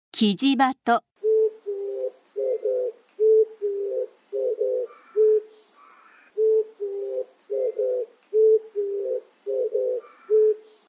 キジバト
【鳴き声】普段は「クウッ」「プゥー」などと鳴き、オスは「デデッポォーポォー、デデッポォーポォー」と繰り返してさえずる。
キジバトの鳴き声（音楽：179KB）
kijibato3.mp3